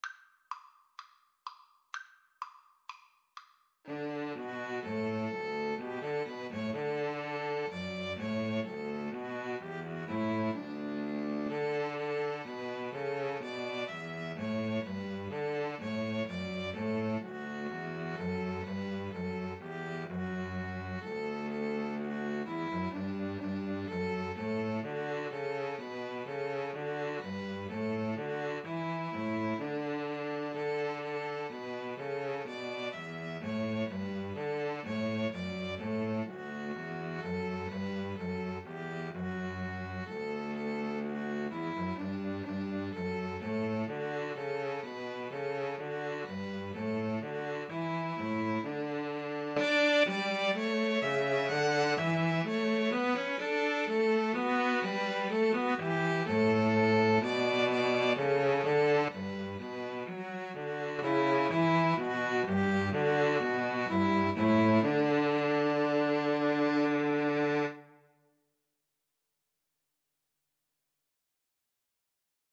Allegro = 126 (View more music marked Allegro)